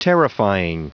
Prononciation du mot terrifying en anglais (fichier audio)
Prononciation du mot : terrifying